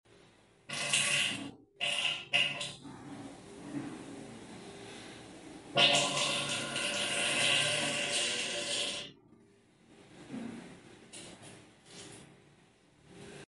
Larva Diarrhea Sounds Effects